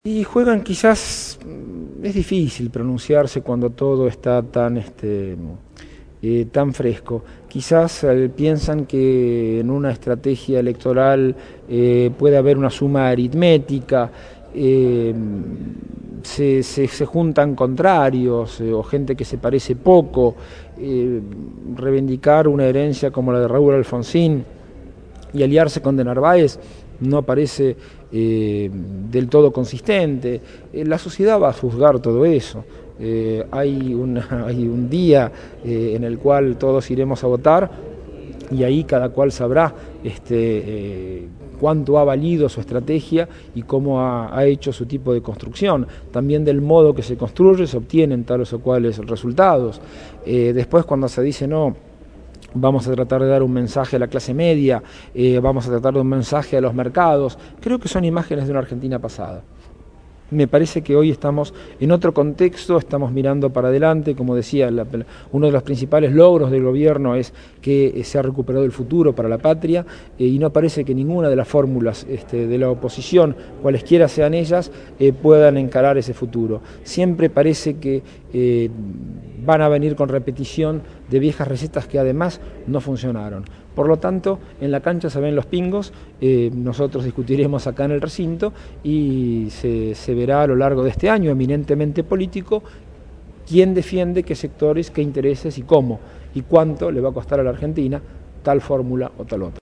Producción y entrevista